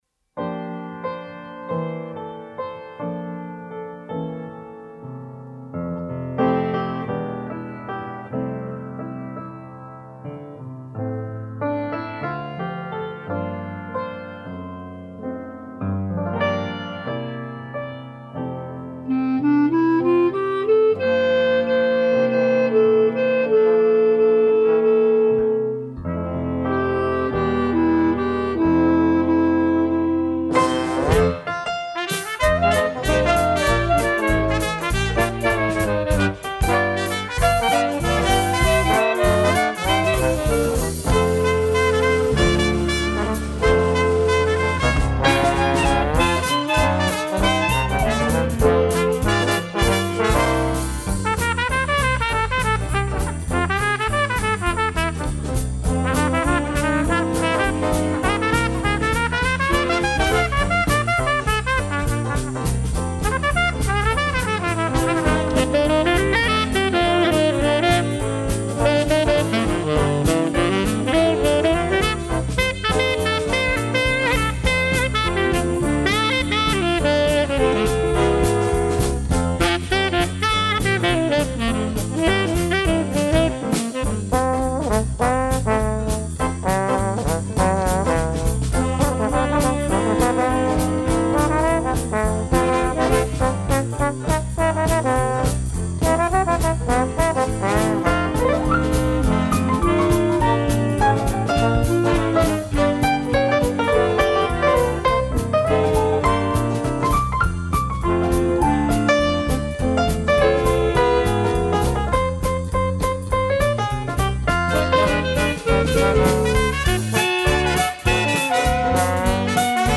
Gattung: Dixieland Combo
Besetzung: Dixieland Combo